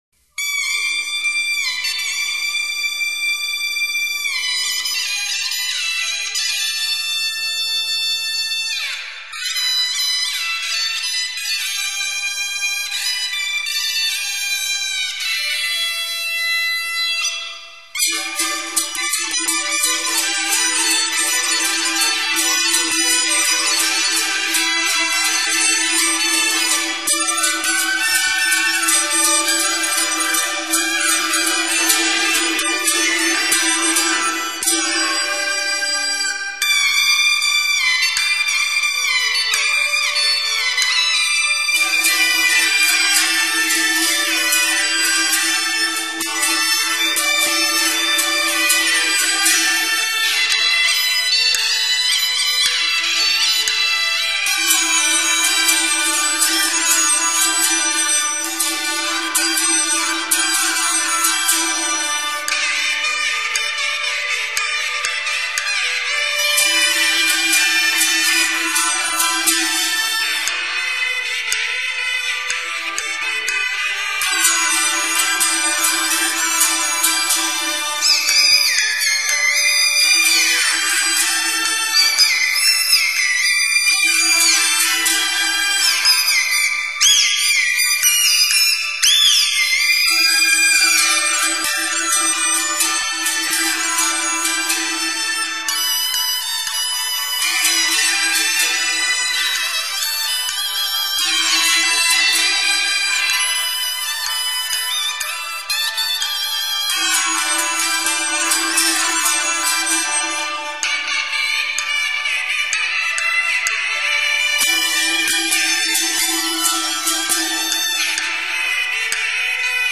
嗩呐音量宏大，音色高亢明亮，擅於表现热烈奔顑M欢快、活泼的情绪。